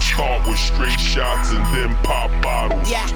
Pop Bottles.wav